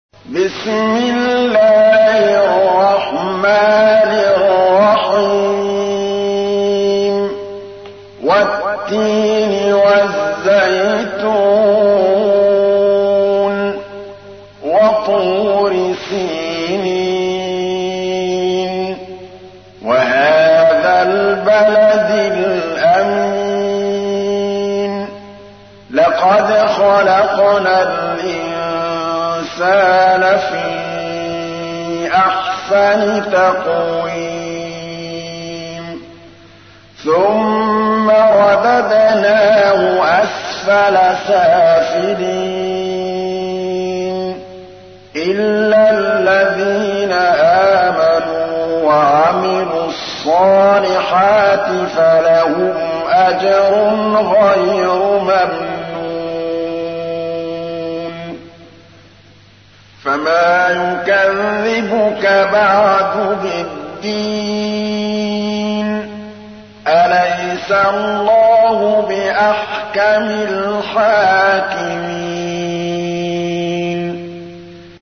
تحميل : 95. سورة التين / القارئ محمود الطبلاوي / القرآن الكريم / موقع يا حسين